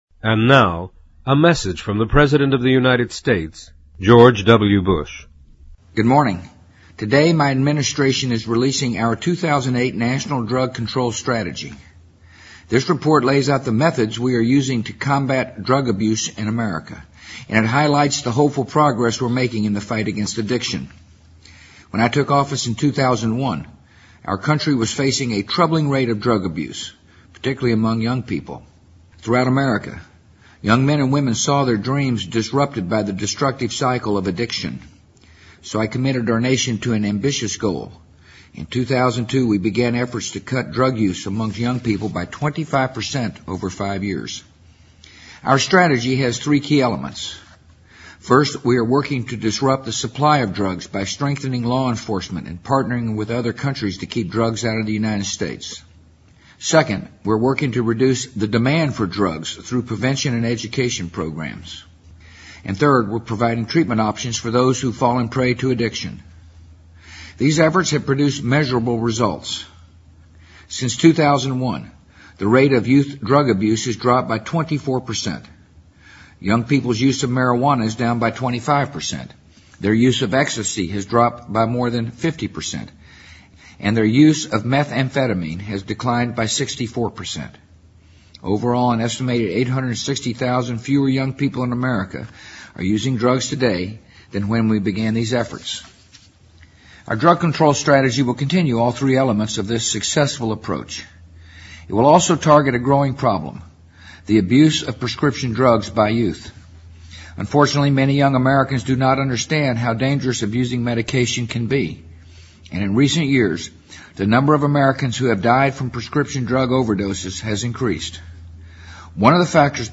【美国总统电台演说】2008-03-01 听力文件下载—在线英语听力室